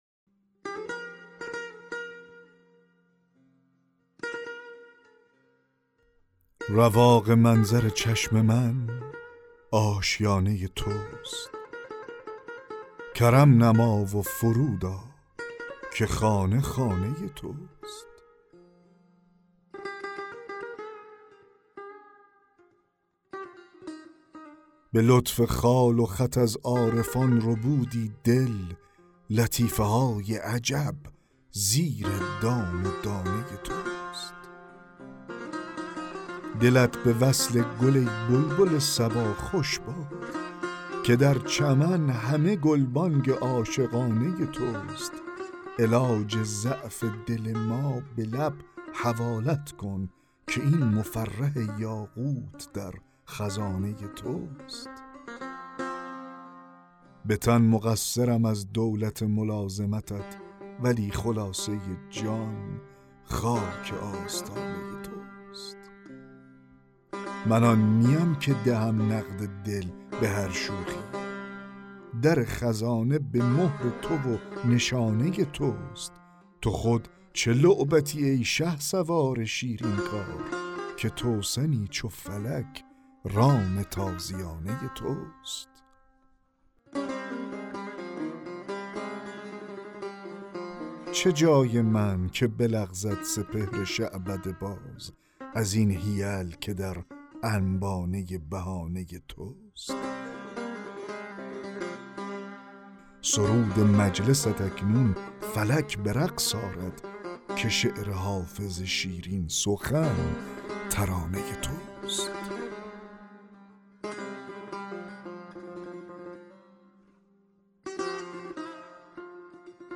دکلمه غزل 34 حافظ
دکلمه غزل رواق منظر چشم من آشیانه توست